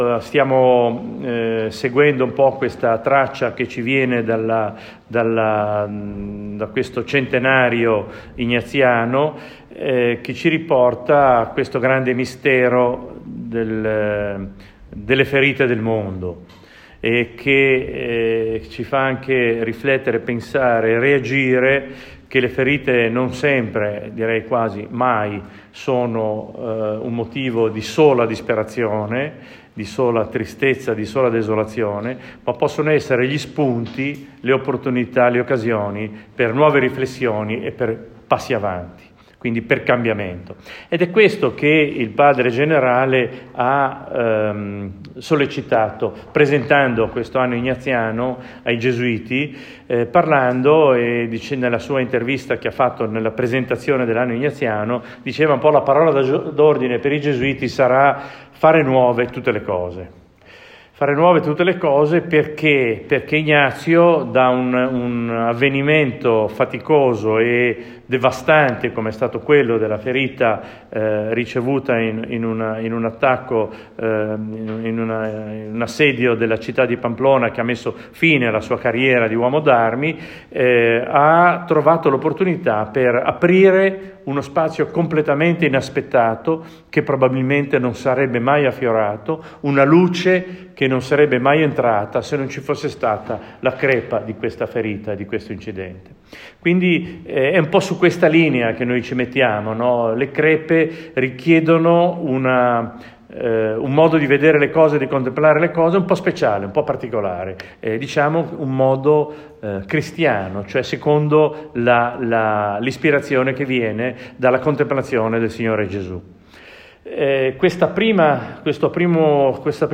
Primo intervento